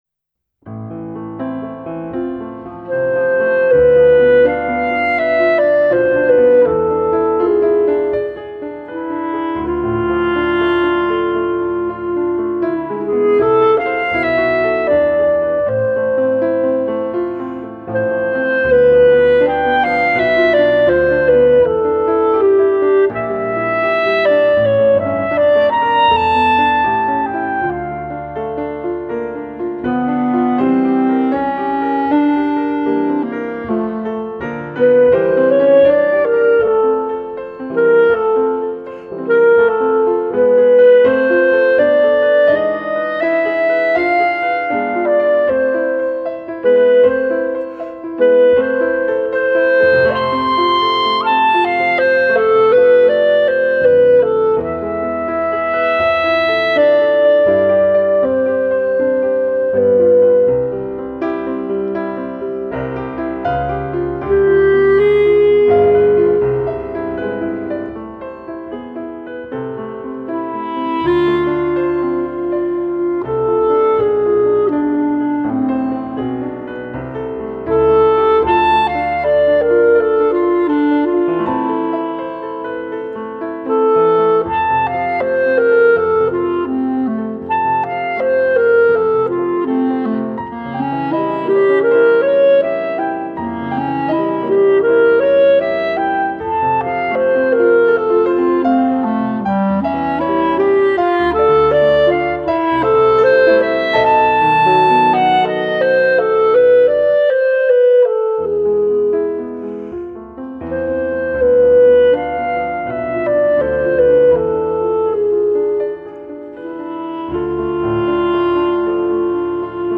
Performance Tempo